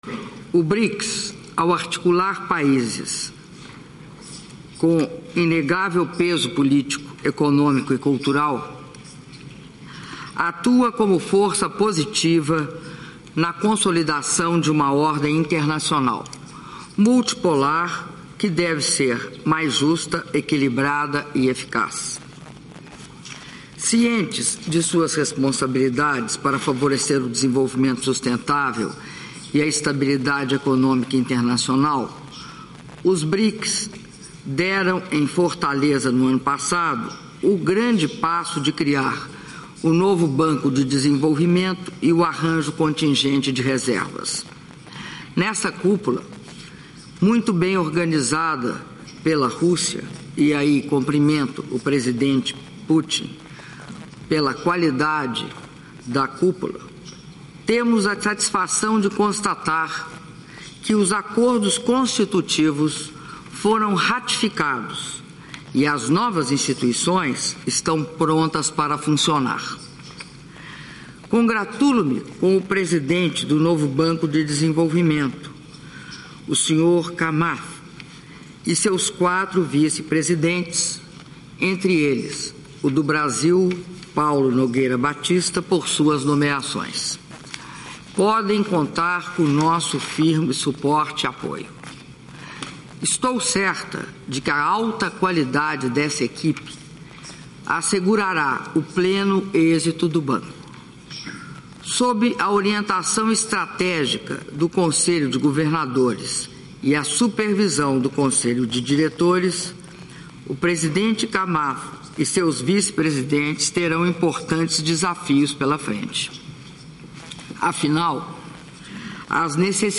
Áudio do Discurso da presidenta da República, Dilma Rousseff, durante Sessão plenária dos chefes de Estado e de Governo dos Brics- UFA/Rússia- (12min12s)